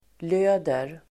Ladda ner uttalet
löda verb, solder Grammatikkommentar: A & x Uttal: [l'ö:der] Böjningar: lödde, lött, löd, löda, löder Definition: sammanfoga metallbitar med smält metall solder verb, löda Grammatikkommentar: transitivt